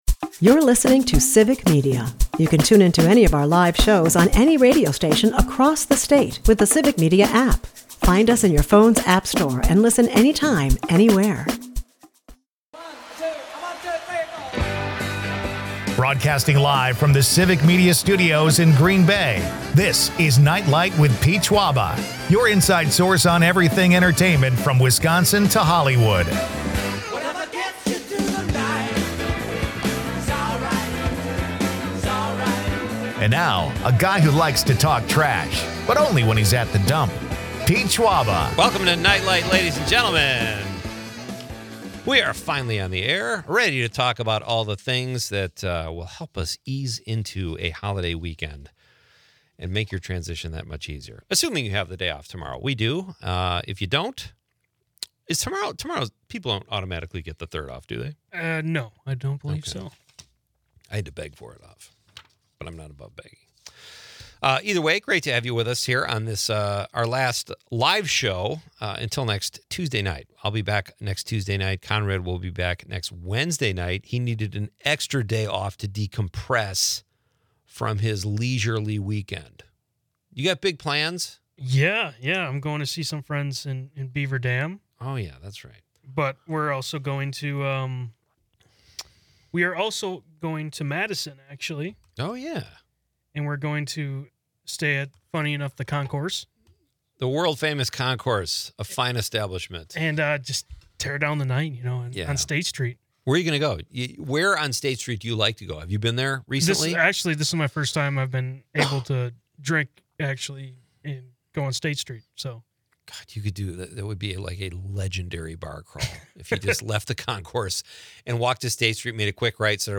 Listeners chime in with their favorites.